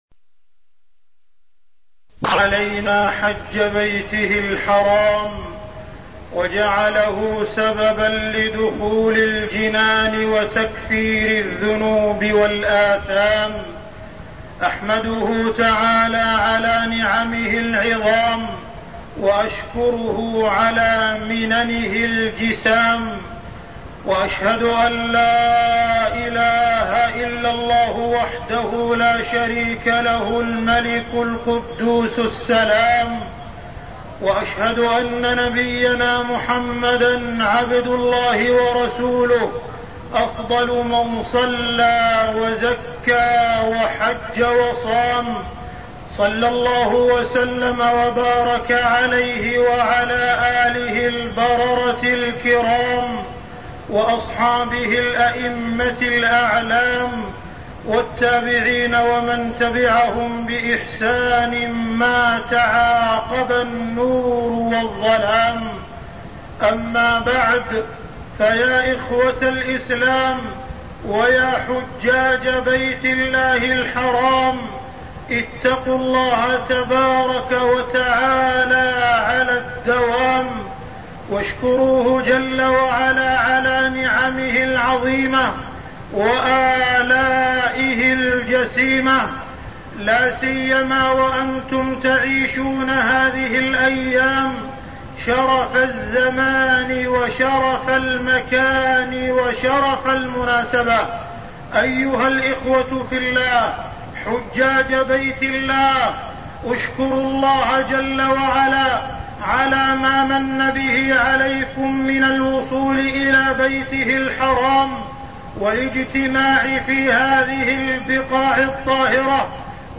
تاريخ النشر ٢٣ ذو القعدة ١٤١٣ هـ المكان: المسجد الحرام الشيخ: معالي الشيخ أ.د. عبدالرحمن بن عبدالعزيز السديس معالي الشيخ أ.د. عبدالرحمن بن عبدالعزيز السديس الحج إلى بيت الله الحرام